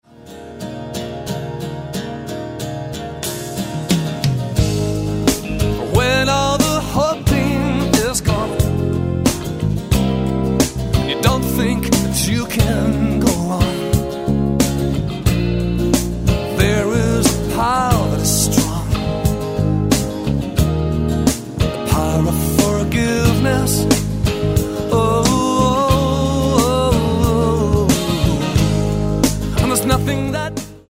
saxophone
backing vocals